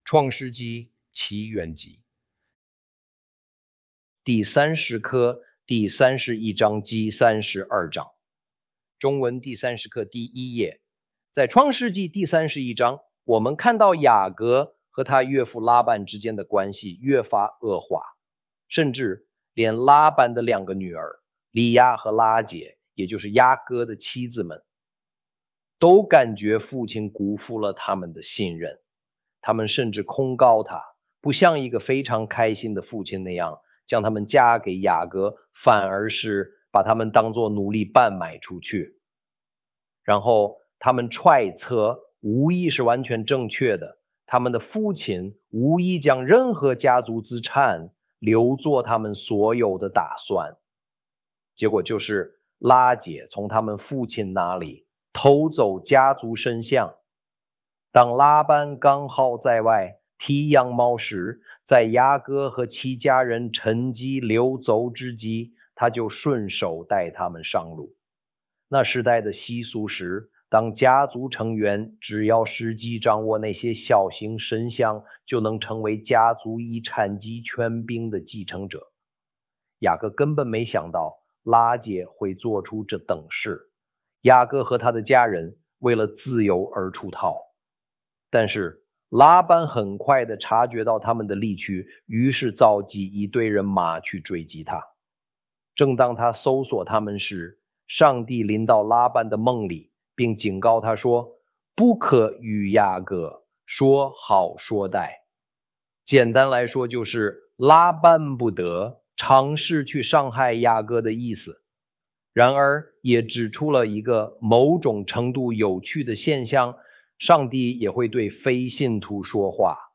創世紀(起源紀) 第三十課-第三十一章及三十二章 中文第30課第1頁 Explore Torah and the New Testament with historical context.